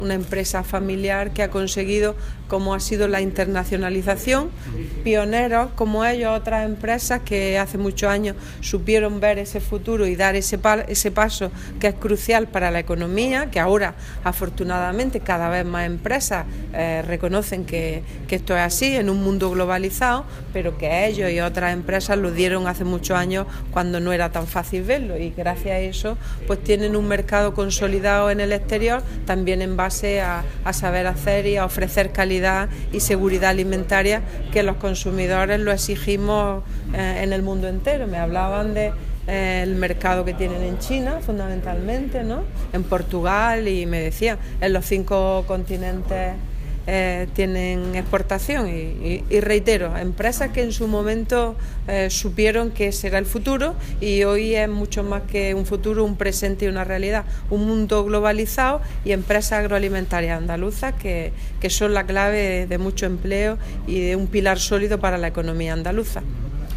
Declaraciones consejera sector agroalimentario